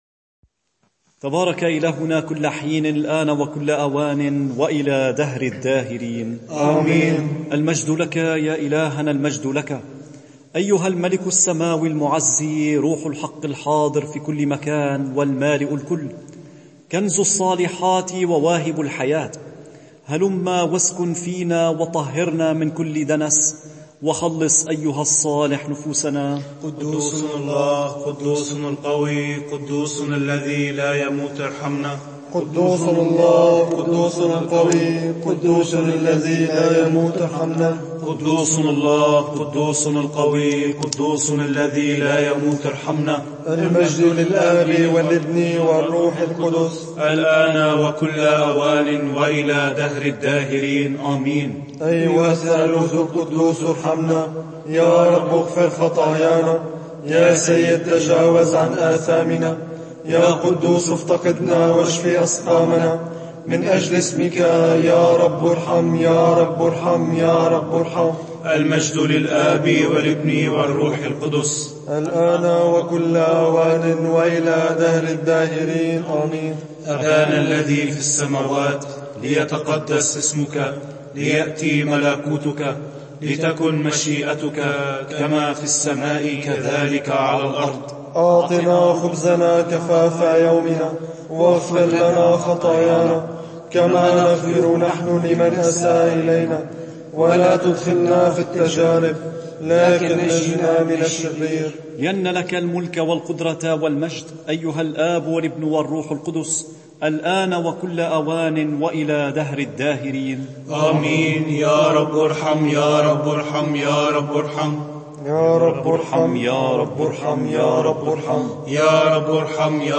صلاة نصف الليل رهبان